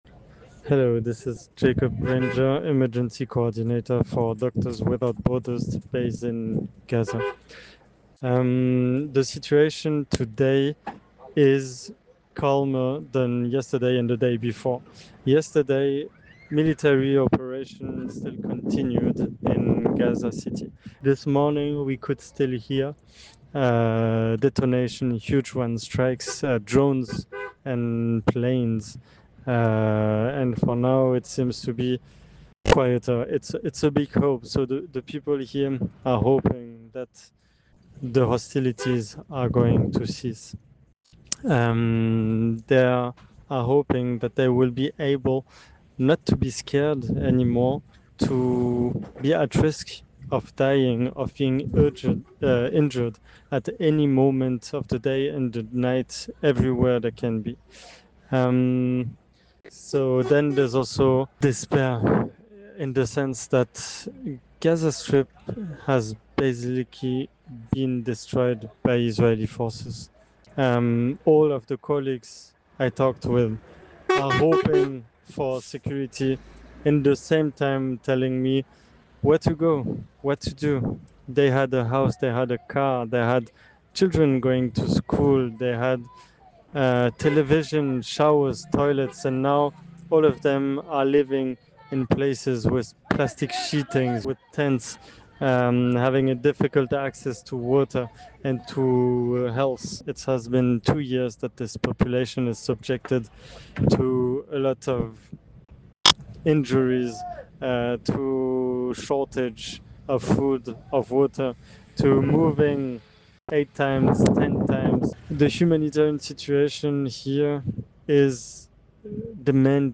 Voice note cease fire MSF.mp3